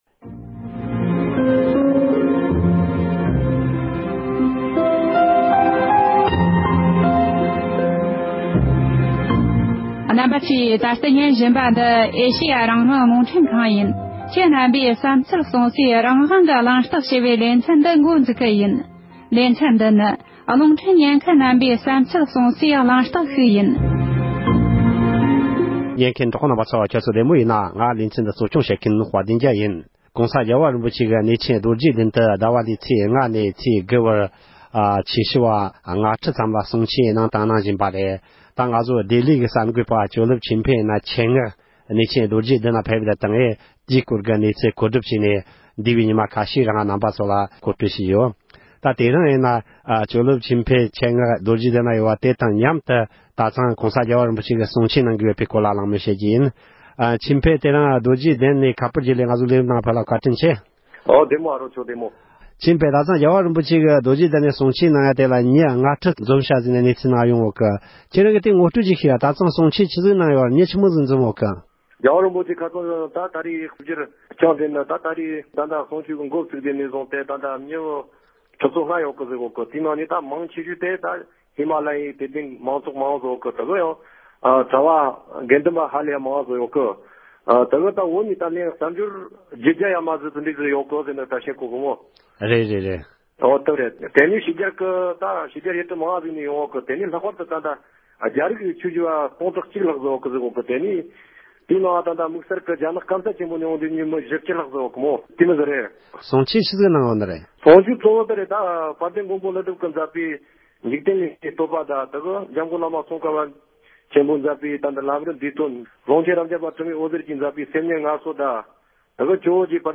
གླེང་མོལ་ཞུས་པར་གསན་རོགས༎